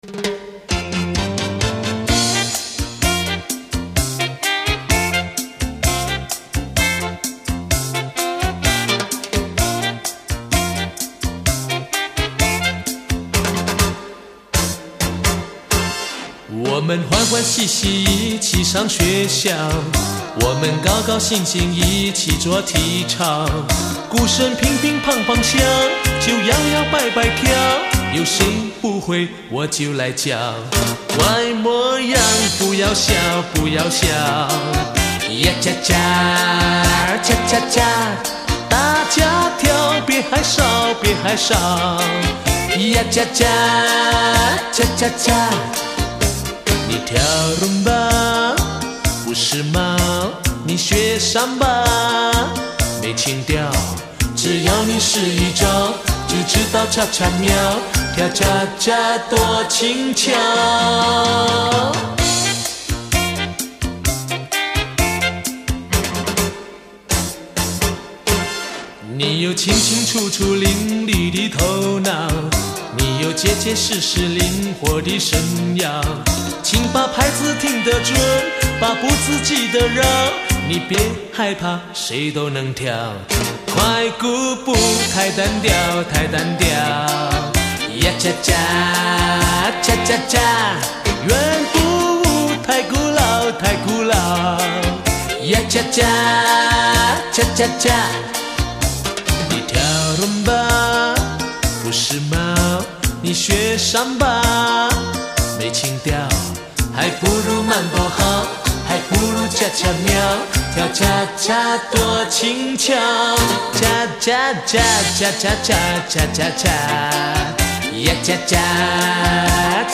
发行地区：新马